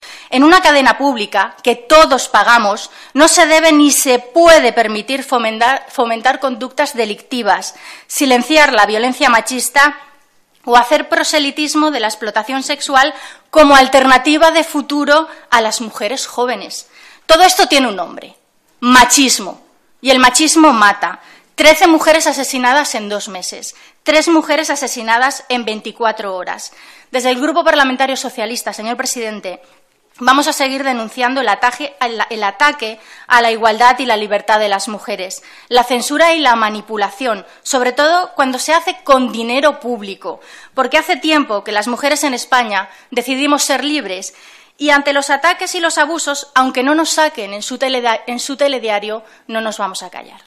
Carmen Montón denuncia el machismo en la RTVE del PP .Comisión de control de RTVEdel Congreso. 27/02/14